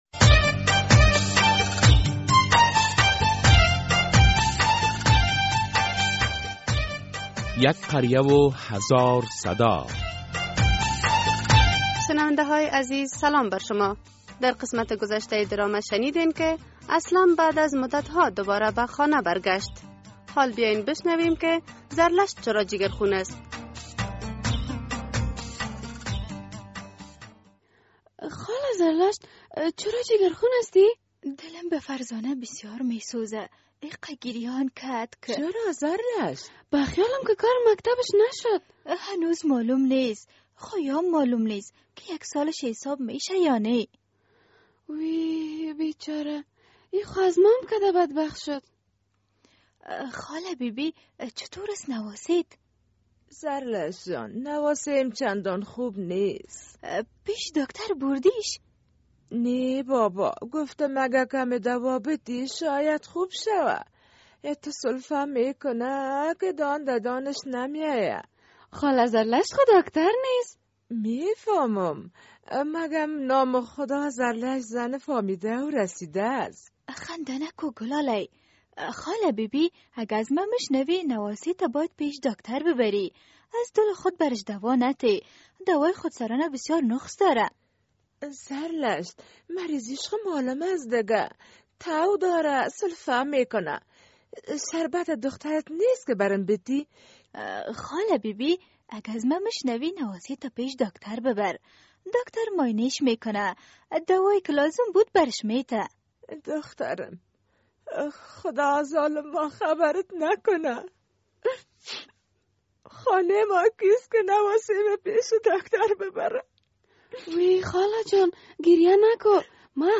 در این درامه که موضوعات مختلف مدنی، دینی، اخلاقی، اجتماعی و حقوقی بیان می گردد هر هفته به روز های دوشنبه ساعت ۳:۳۰ عصر از رادیو آزادی نشر می گردد...